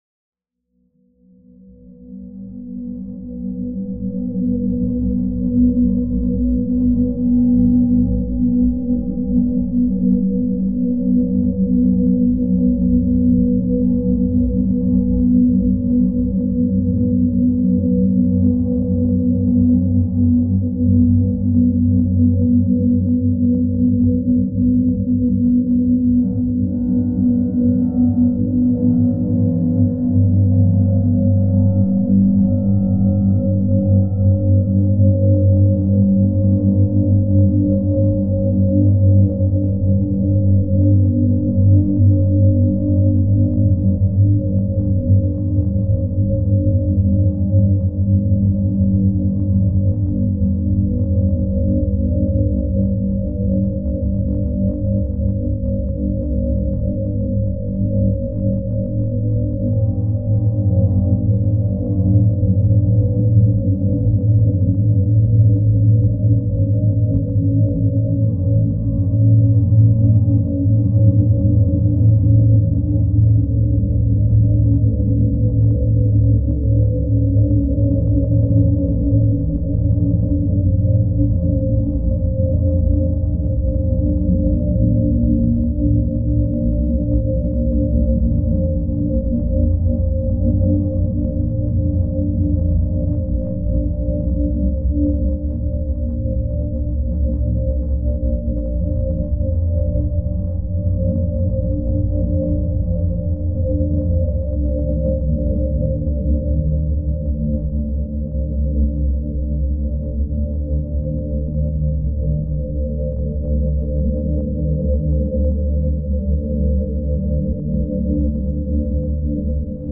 Genre: Drone Ambient.